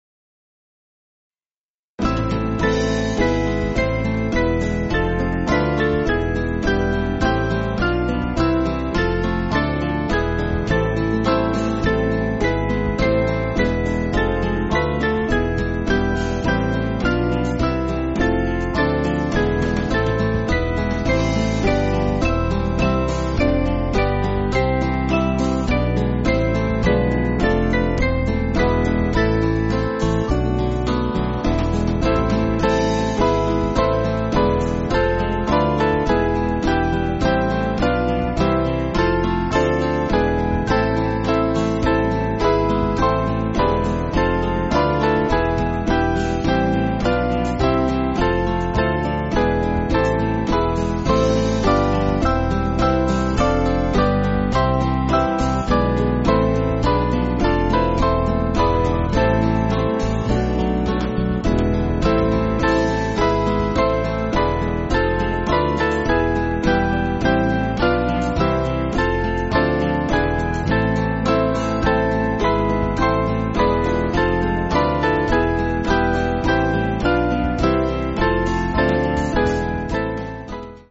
Small Band
(CM)   3/Am